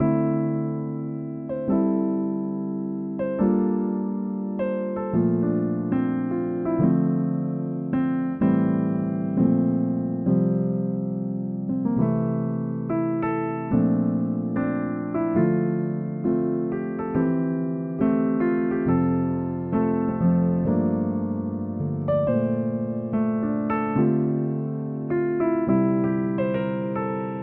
02 midpiano.ogg